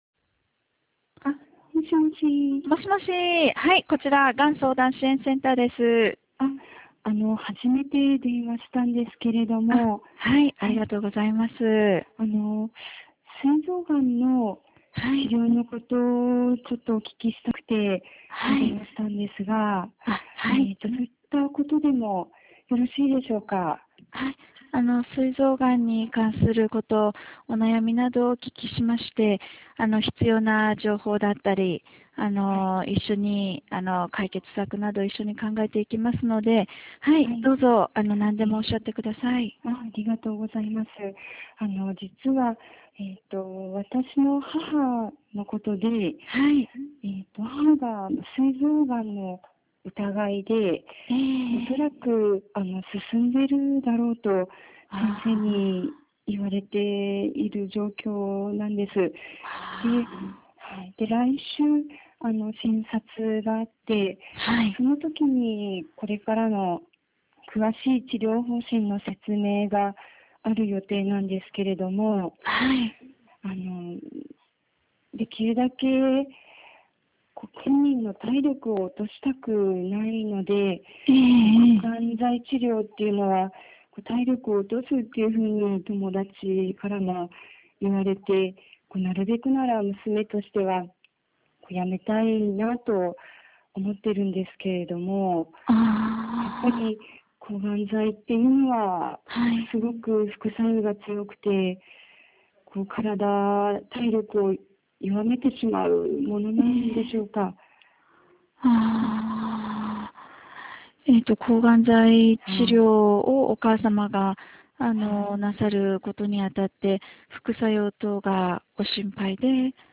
相談音声事例課題（事例1）：[国立がん研究センター がん情報サービス 医療関係者の方へ]
関連情報 事前課題を実施する際の留意点 電話相談事例（事例1）（13分33秒） 電話相談事例逐語録（事例1） がん相談対応評価表 問い合わせ先 本事例を使用して研修を開催される主催者へお問い合わせください。 電話相談事例の取り扱い 事前課題の事例は模擬事例ですが、保存等されたデータは研修会終了後に各自、破棄していただきますようお願い申し上げます。